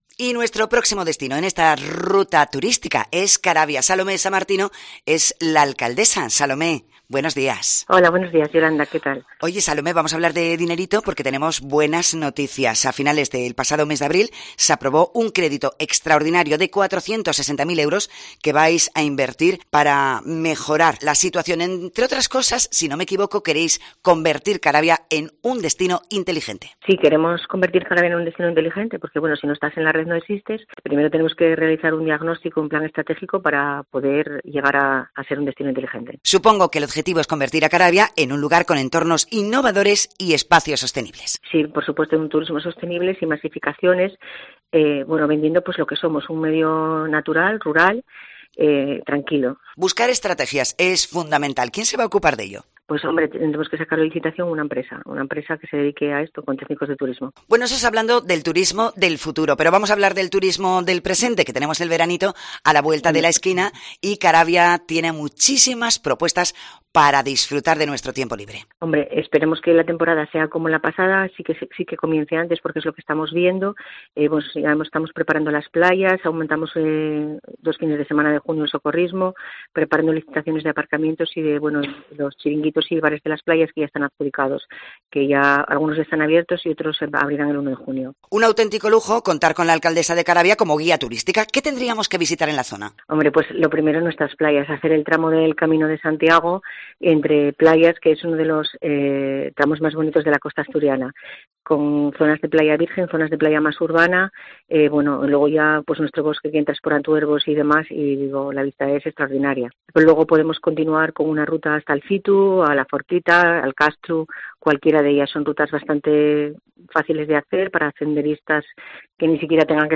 Entrevista a la alcaldesa de Caravia, Salomé Samartino